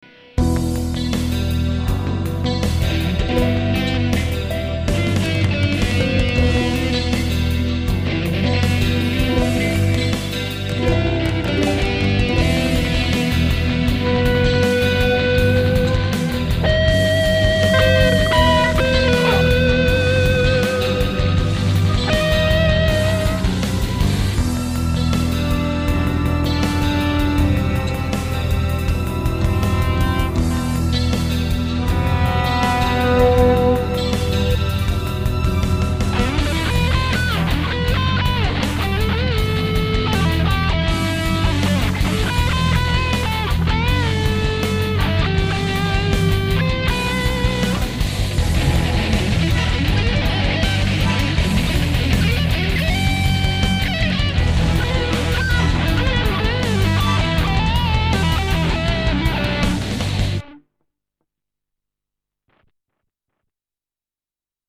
eux pour le saturé, j'ai un sample mais très métal et un peu criard
car la gratte j'avais fais qlq modifs sur le circuit pour etre percant ,